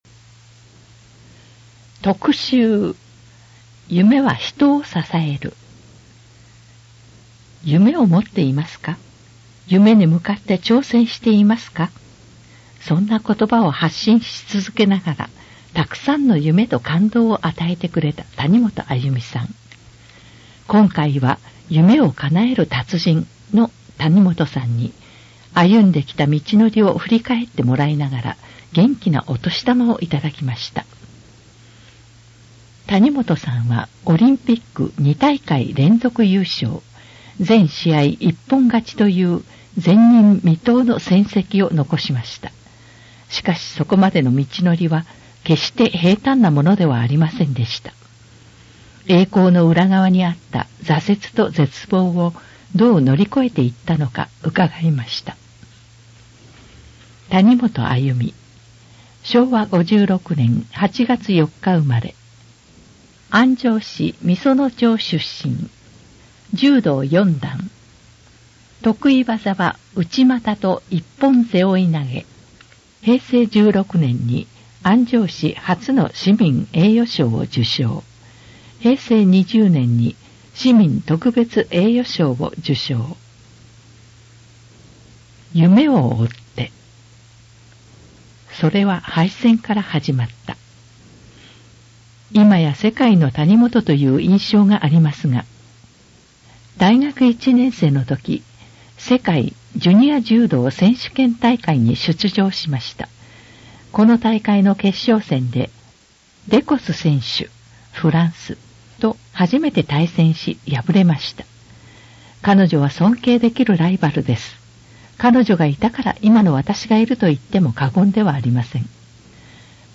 なお、以上の音声データは、「音訳ボランティア安城ひびきの会」の協力で作成しています。